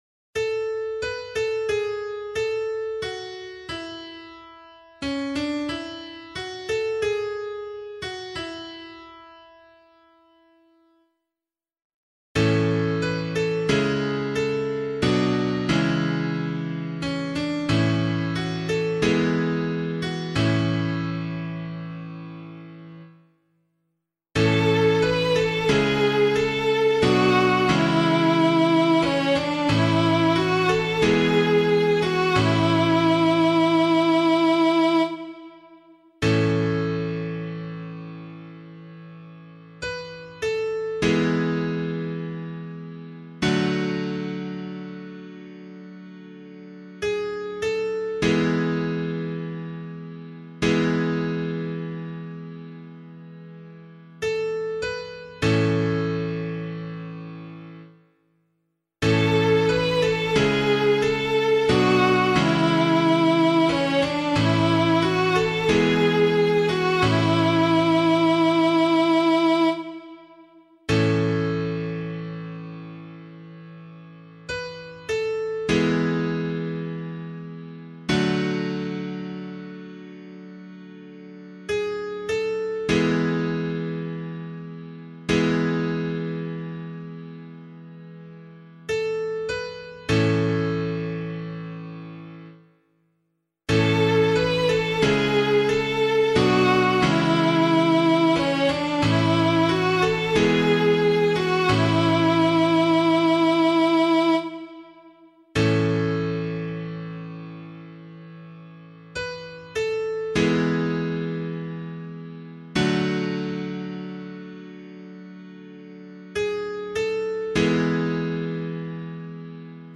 pianovocal
034 Corpus Christi Psalm C [LiturgyShare 8 - Oz] - piano.mp3